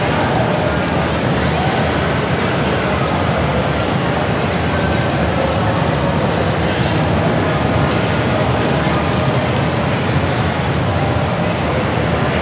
rr3-assets/files/.depot/audio/sfx/ambience/ambience_raceday.wav
ambience_raceday.wav